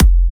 Kick 21 (Reason).wav